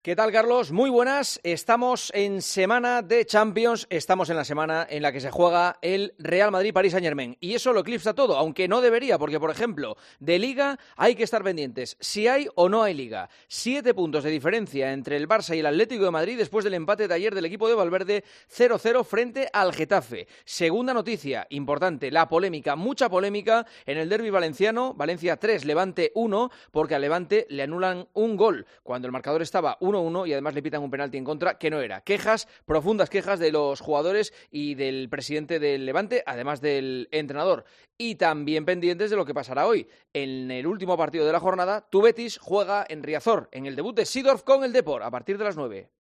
El comentario de Juanma Castaño 'EL PARTIDAZO' DE COPE